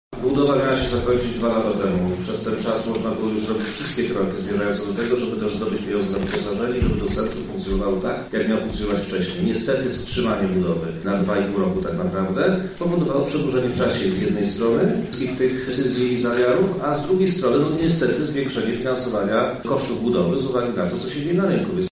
O kosztach budowy mówi Wojewoda Lubelski Przemysław Czarnek: